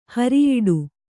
♪ hariyiḍu